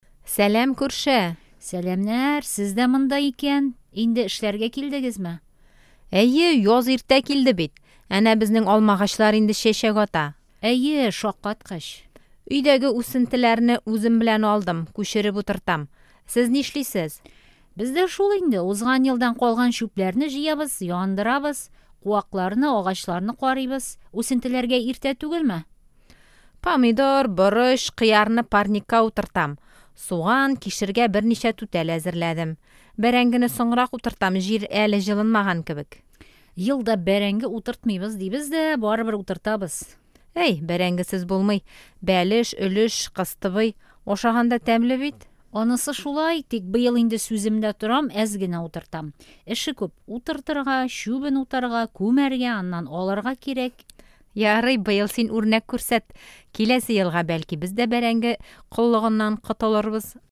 Аудирование. Прослушайте диалог двух соседей по даче:
Диалог